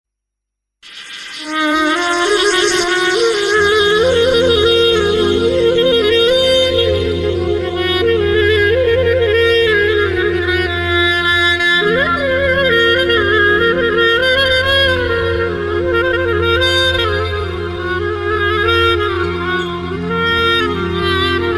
آهنگ بستکی